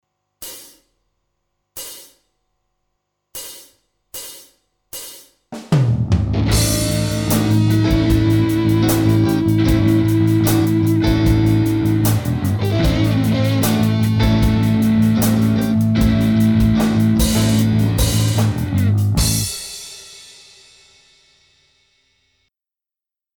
Προβολή συνημμένου song.mp3 Έπαιζα σήμερα στο studio για καμία ώρα, Μια χαρά ξεκα*****σα.
Γι αυτή την μπουρδα , με σχεδόν όλα στημένα , μου πήρε μιση ώρα (να στήσω και μικρόφωνα κ.λ.π , και παίξε ντραμς και παρε τη μια κιθαρα και μετα το μπάσο...πφφφγφφ)/.